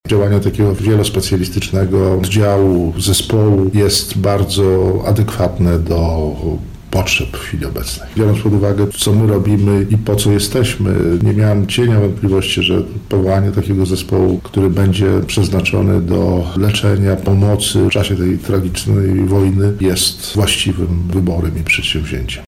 • mówi rektor Uniwersytetu Medycznego w Lublinie prof. Wojciech Załuska.